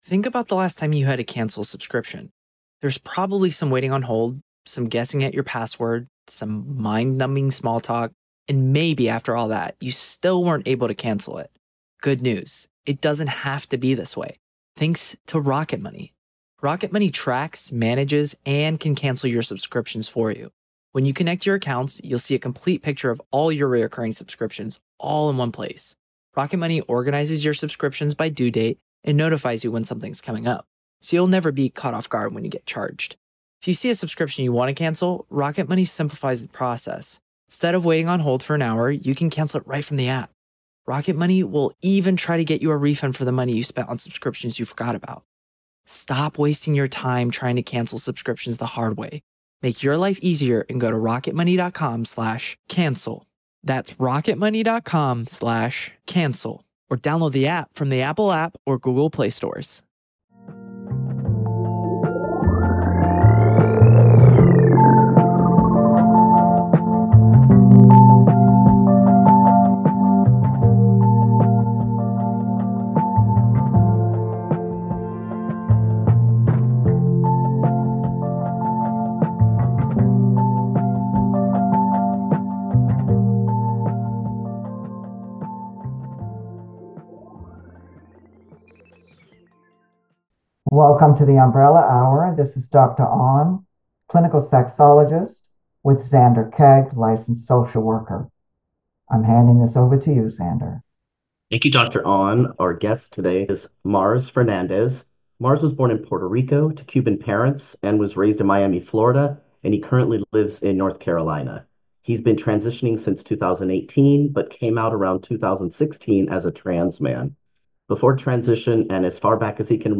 interviews that examine the lives of LGBT people from diverse vantage points